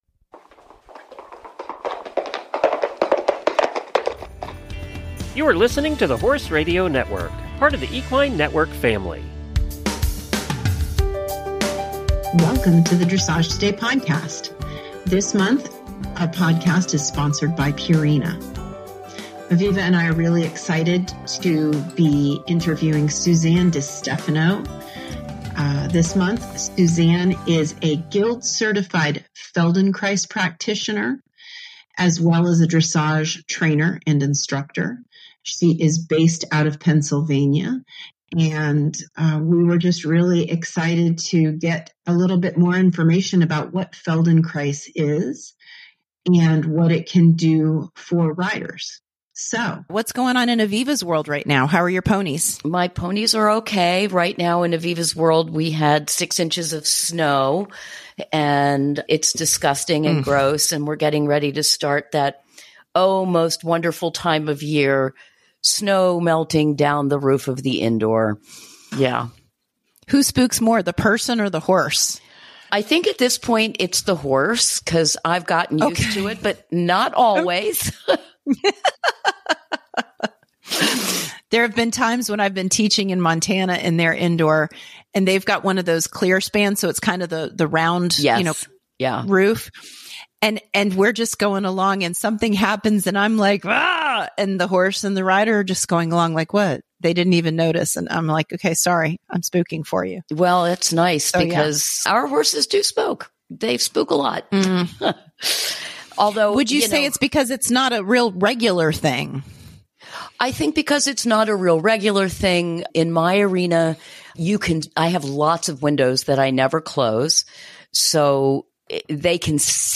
We have a word from Purina, who is the sponsor of this month’s podcast.